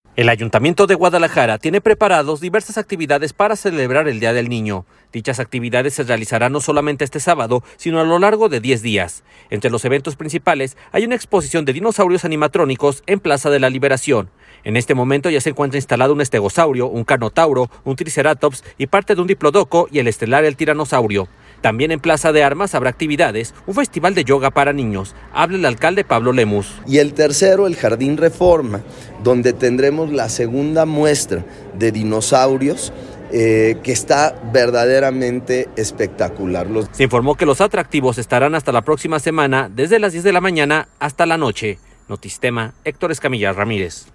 Habla el alcalde Pablo Lemus.